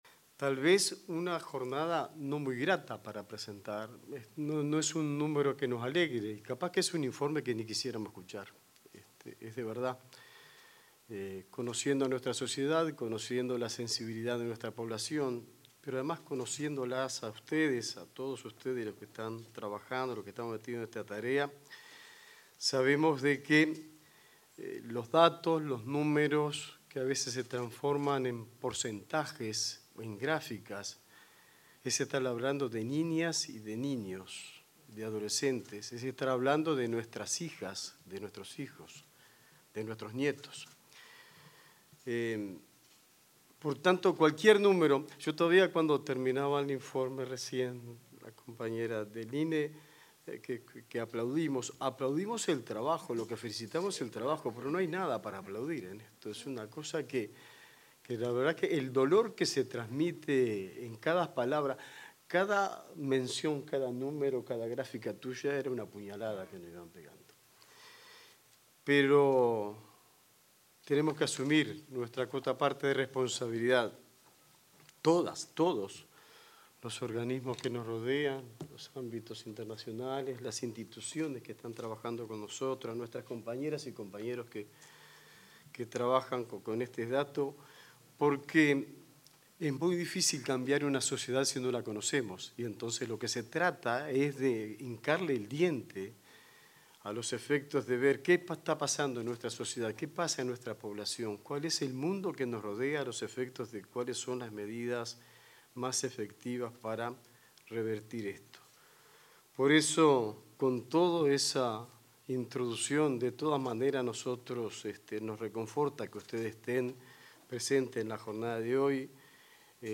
Palabras del ministro de Trabajo, Juan Castillo
Palabras del ministro de Trabajo, Juan Castillo 08/09/2025 Compartir Facebook X Copiar enlace WhatsApp LinkedIn El ministro de Trabajo y Seguridad Social, Juan Castillo, se expresó durante la presentación de los resultados de la Encuesta Nacional sobre las Actividades de Niñas, Niños y Adolescentes 2024.